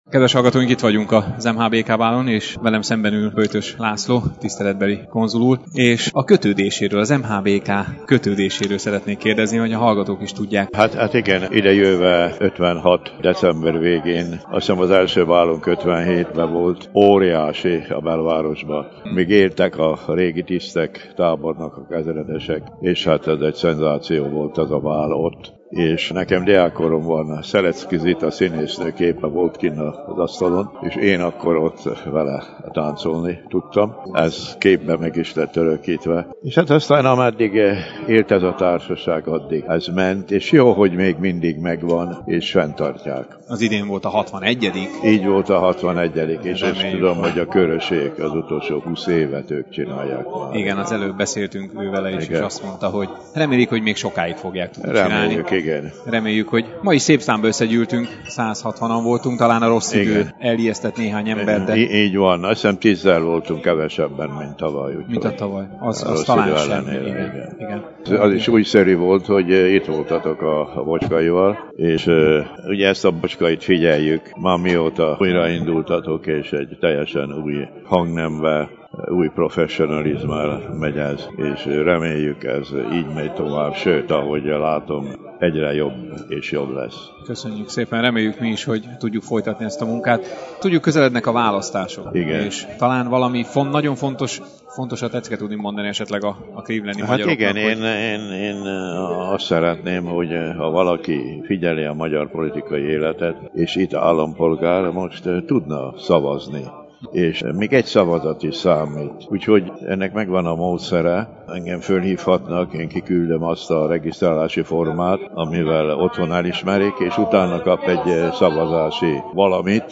Beszámoló a 2014-es clevelandi MHBK bálról – Bocskai Rádió
De még mielőtt mindenki a nyakába vette volna újra a havas utakat , Böjtös László tiszteletbeli konzul úr is megosztott néhány értékes gondolatot velem és rajtunk keresztül önökkel is.